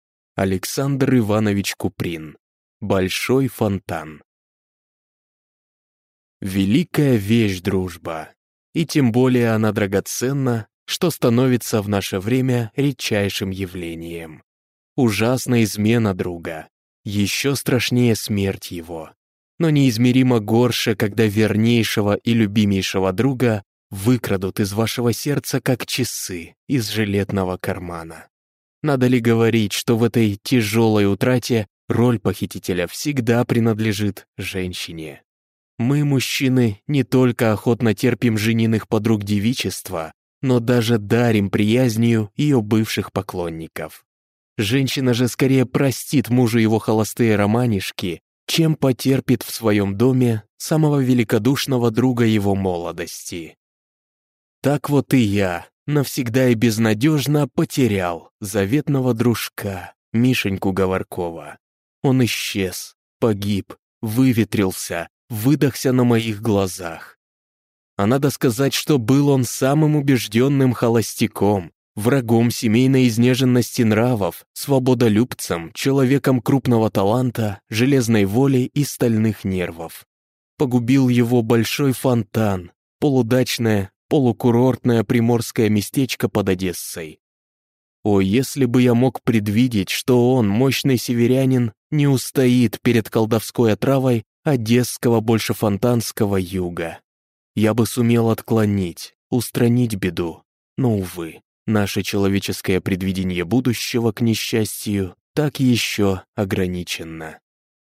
Аудиокнига Большой Фонтан | Библиотека аудиокниг